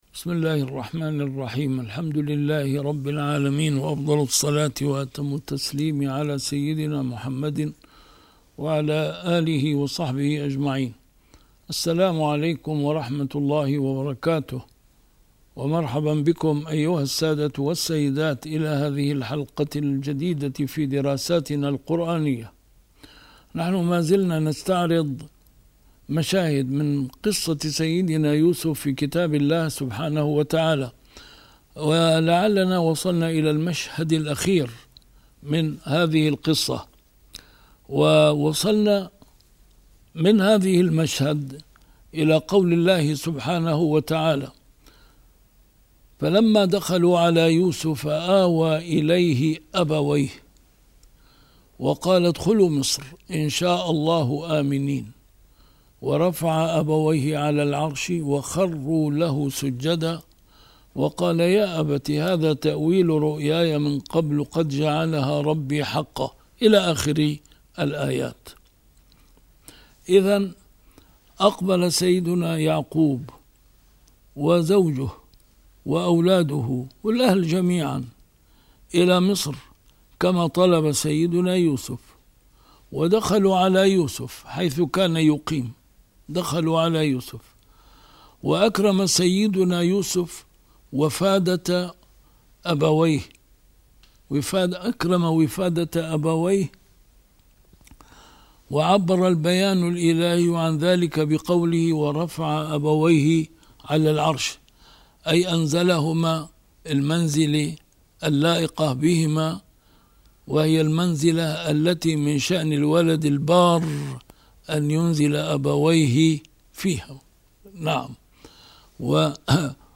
مشاهد من قصة سيدنا يوسف في القرآن الكريم - A MARTYR SCHOLAR: IMAM MUHAMMAD SAEED RAMADAN AL-BOUTI - الدروس العلمية - فقه عام - 12 - مناجاة سيدنا يوسف لله عز وجل